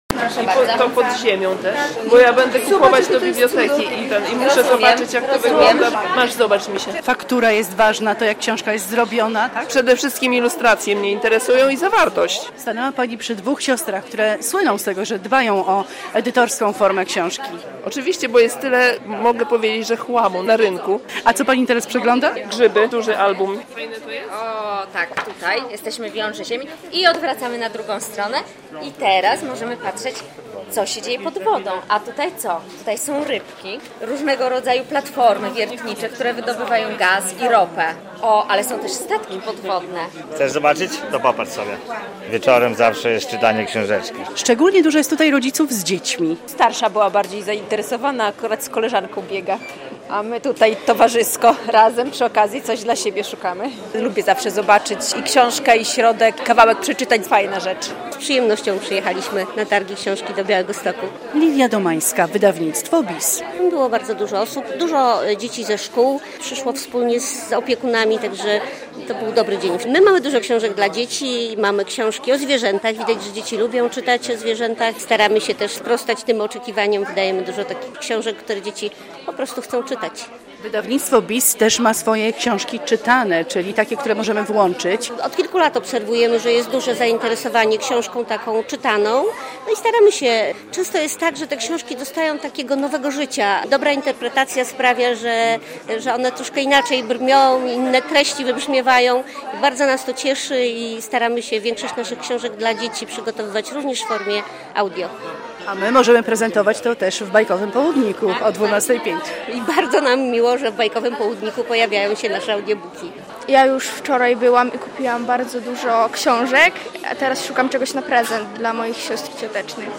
9. Targi Książki w Białymstoku - relacja